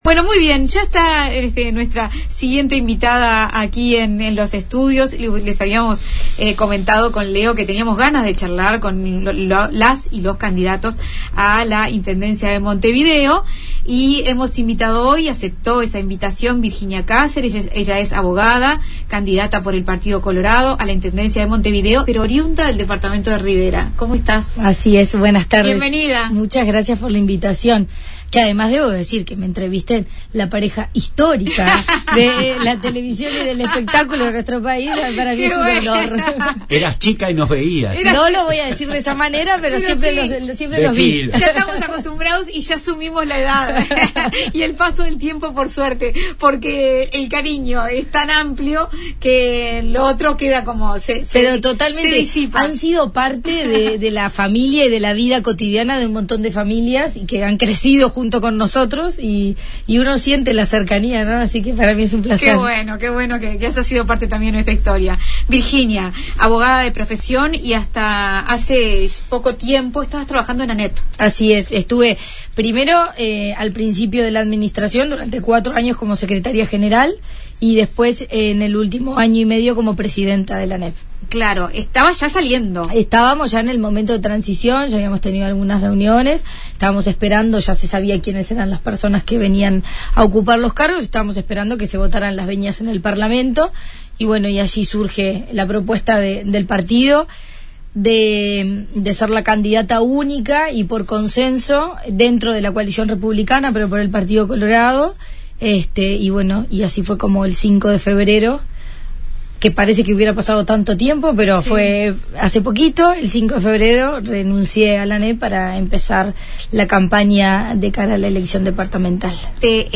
segú contó en entrevista, con Buenas tardes Uruguay.